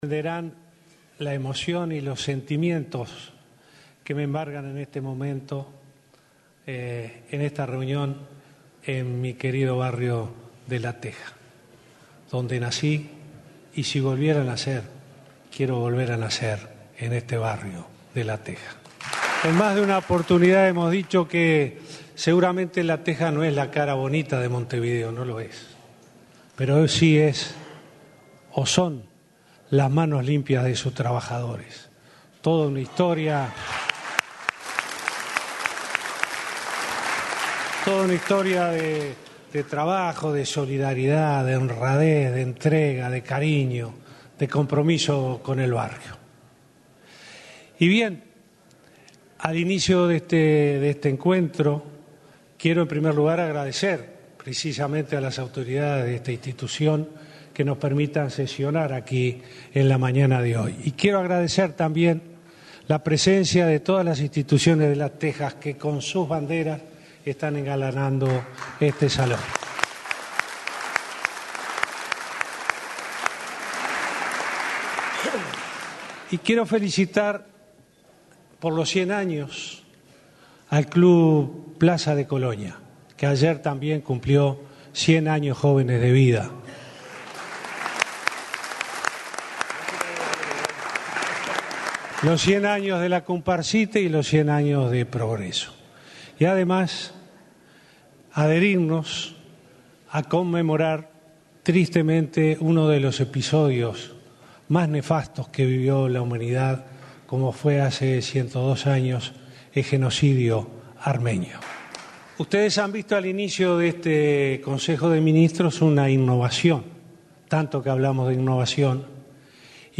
Durante la apertura del Consejo de Ministros de este lunes en La Teja, Montevideo, el presidente Tabaré Vázquez reconoció sus sentimientos hacia el barrio La Teja que lo vio nacer y crecer y destacó la historia de trabajo de sus pobladores. Asimismo, celebró los 100 años de los clubes Plaza Colonia y Atlético Progreso y adhirió a la conmemoración de los 102 años del genocidio del pueblo armenio.